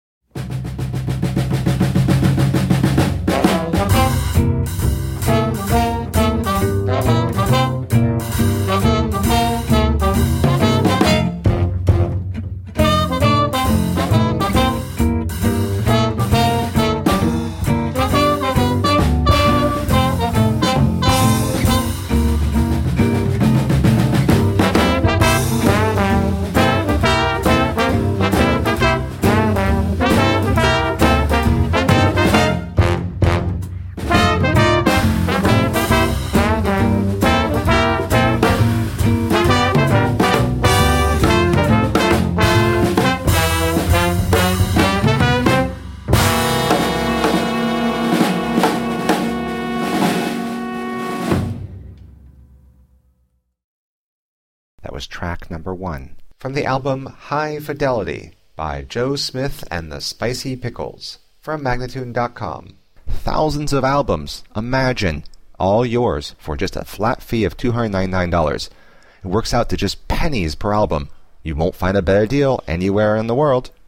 Vintage swing in the 21st century.